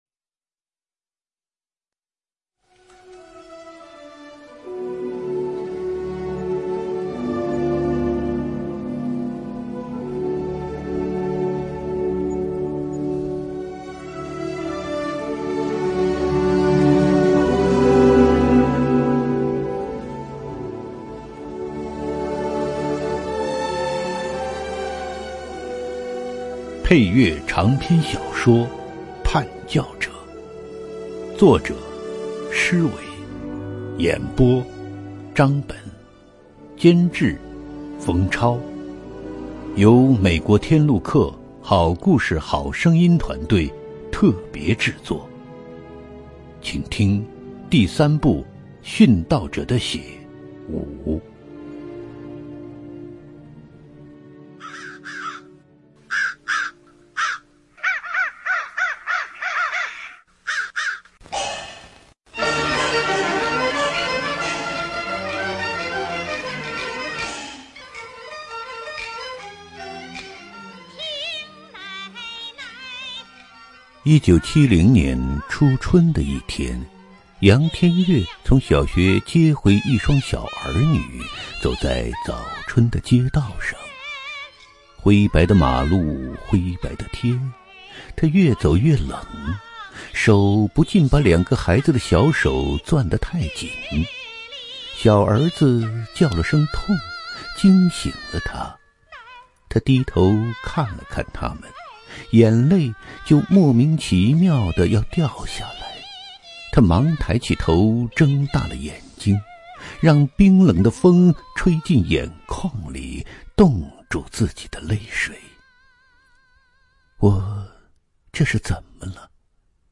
有声书连载：《叛教者》第三部《跟随者：殉道者的血》（第五章） | 普世佳音